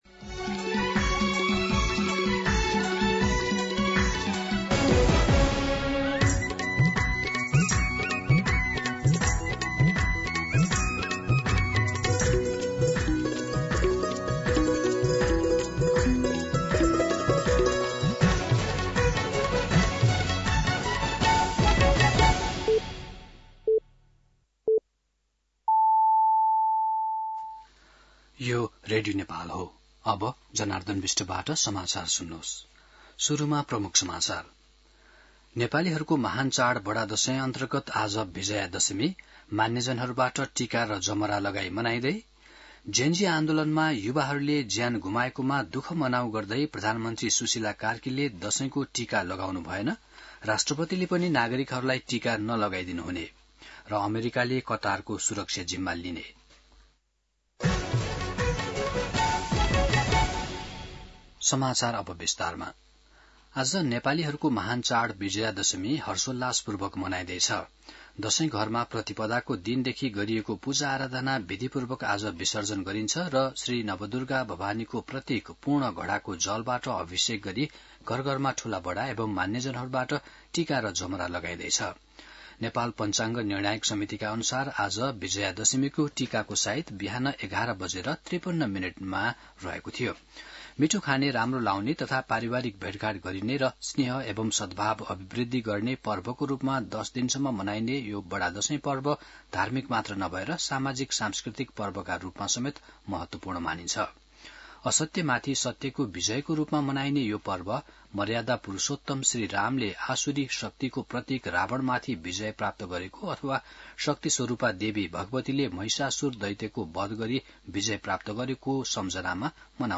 दिउँसो ३ बजेको नेपाली समाचार : १६ असोज , २०८२
3-pm-Nepali-News.mp3